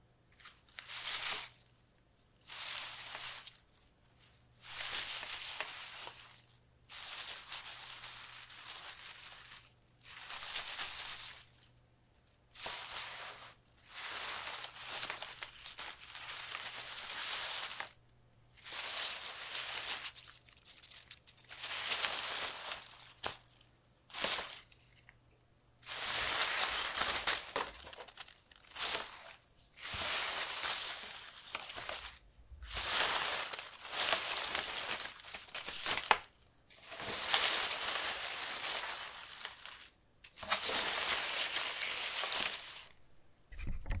随机点滴的声音1 " 落叶飘零
描述：叶子从树上落下，用H2 Zoom Recorder 44.1 wav录制。
Tag: WAV 树叶 沙沙 沙沙